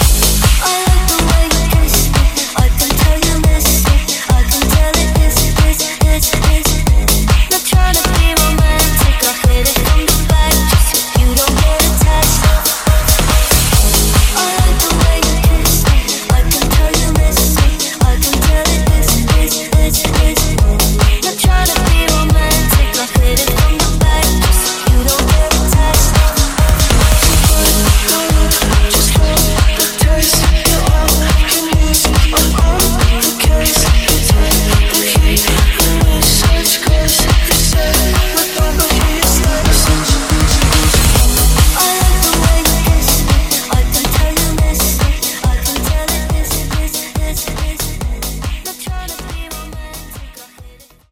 Genre: 2000's Version: Clean BPM: 128 Time